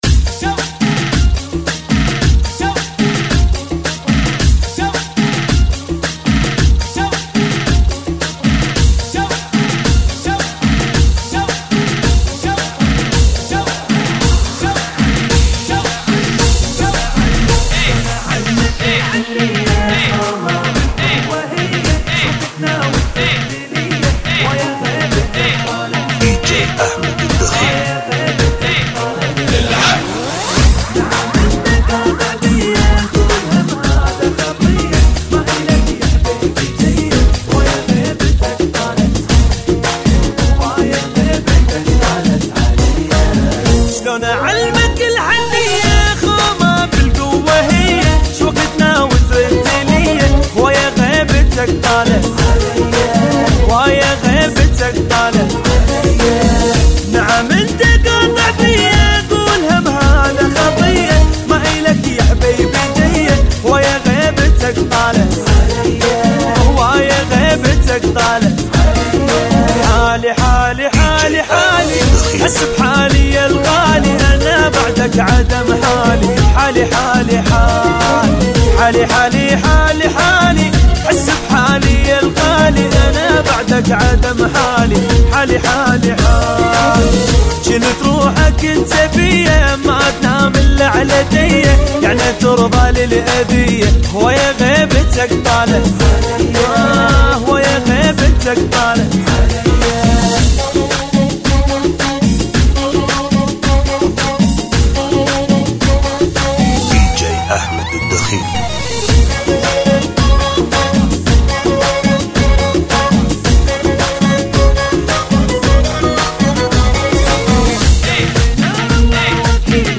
ريمكس
Funky Remix